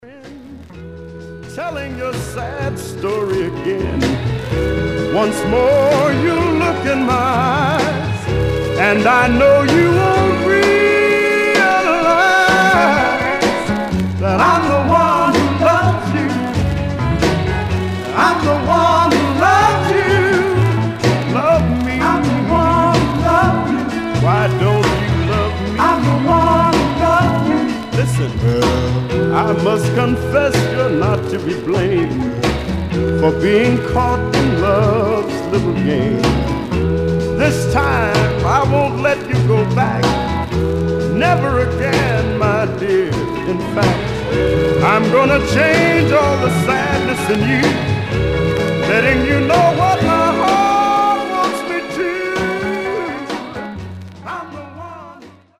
Some surface noise/wear Stereo/mono Mono
Soul